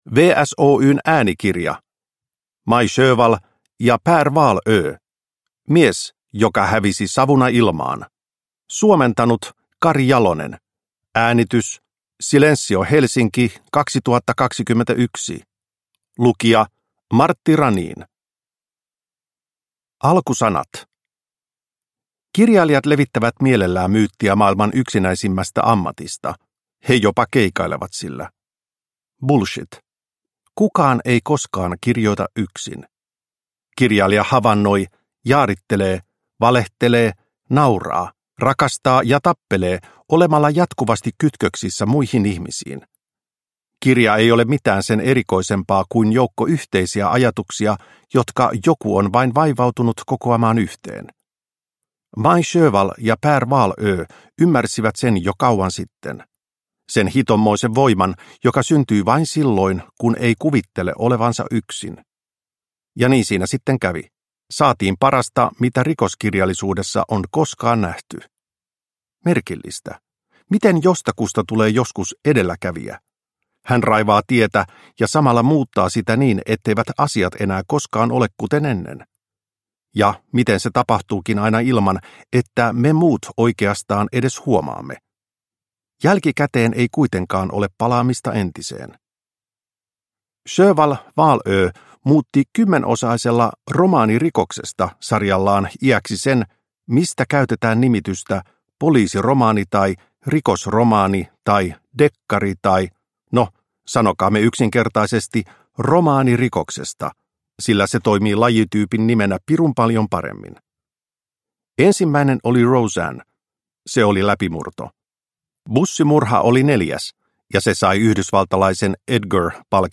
Mies, joka hävisi savuna ilmaan – Ljudbok – Laddas ner